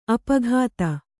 ♪ apaghāta